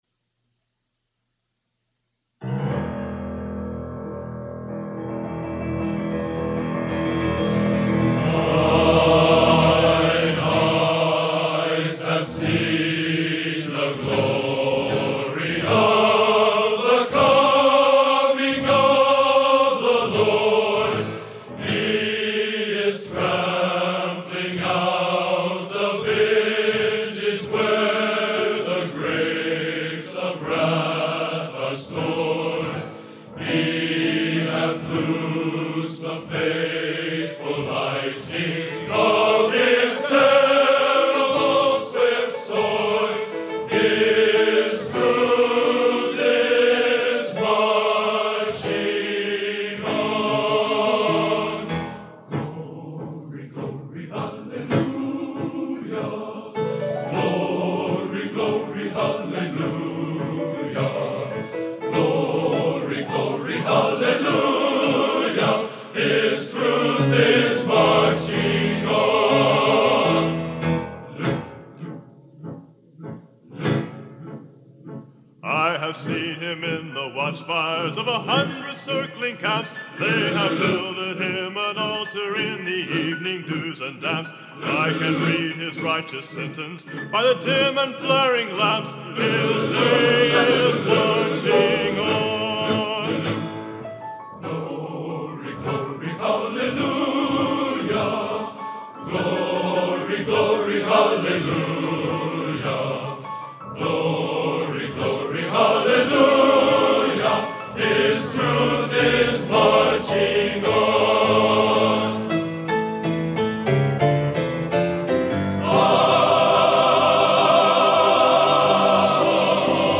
Genre: Patriotic Traditional | Type: Studio Recording